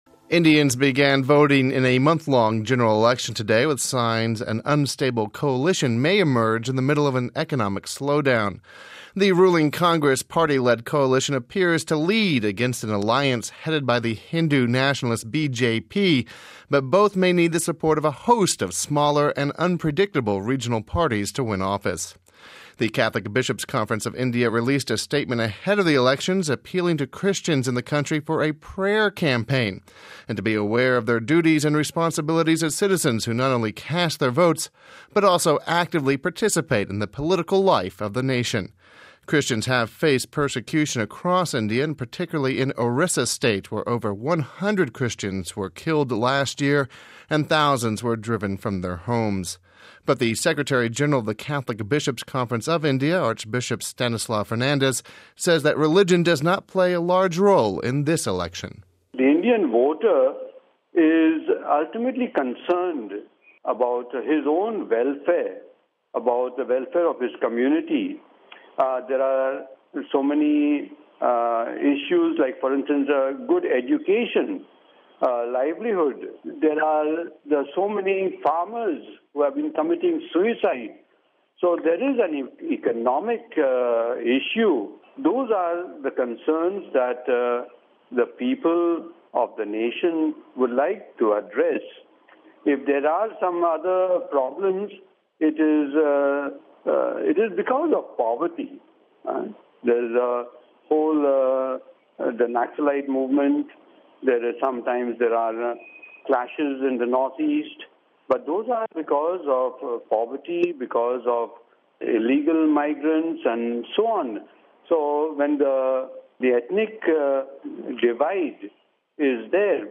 (16 Apr 09 - RV) India has begun its month-long general elections. We spoke to the Secretary General of the Catholic Bishops’ Conference of India, Archbishop Stanislaus Fernandez about the role of religion in the elections...